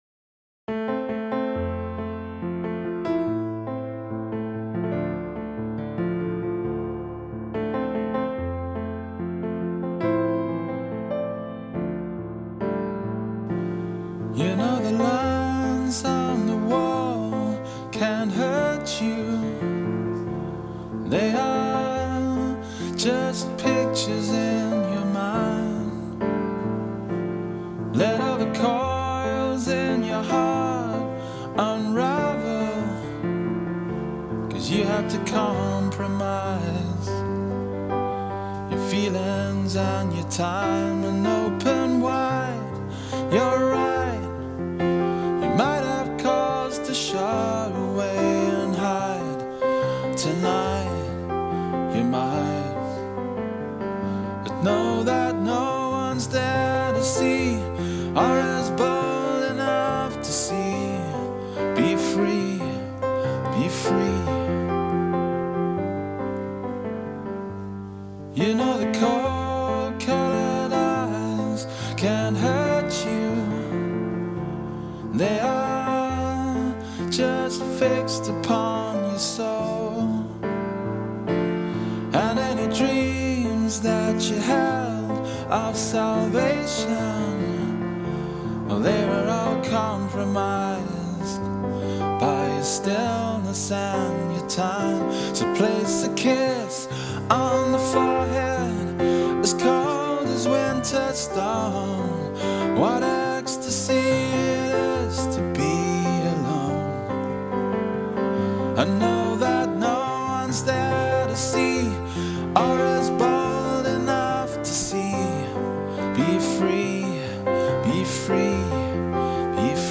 I usually write the music and play the instruments; he sings, writes the singing tune and the lyrics:  Try these